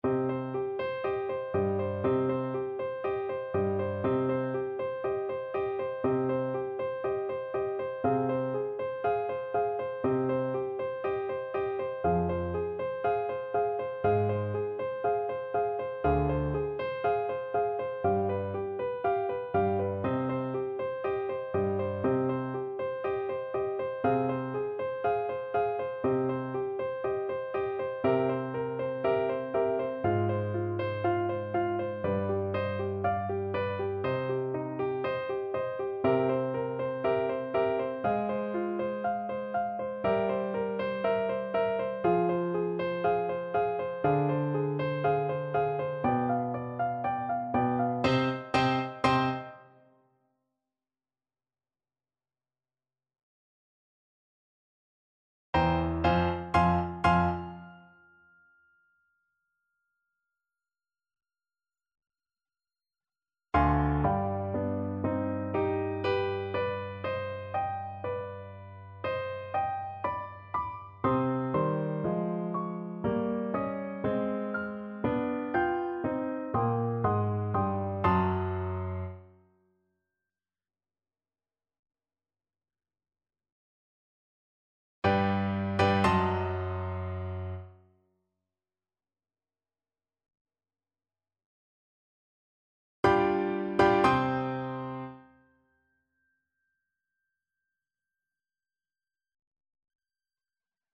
Allegro, molto appassionato (View more music marked Allegro)
Classical (View more Classical Saxophone Music)